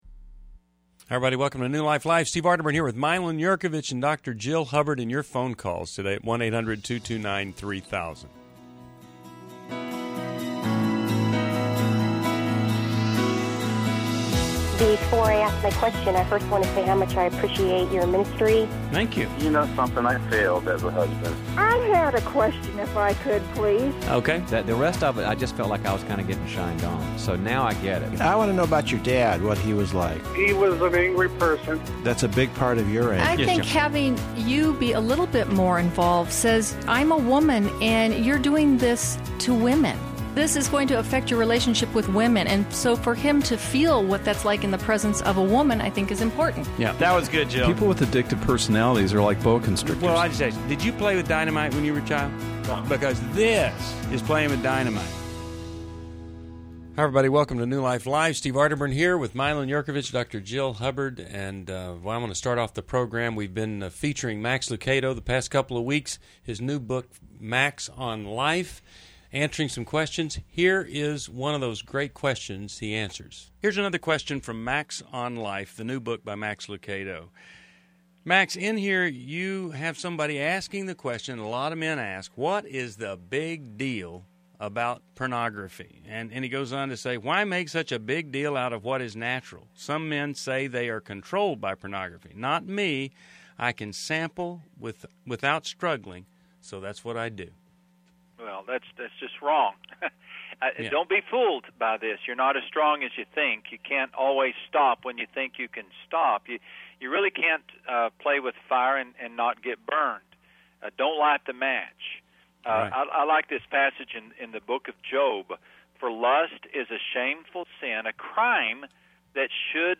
Caller Questions: 1. How do I get back my joy after loss and disability? 2.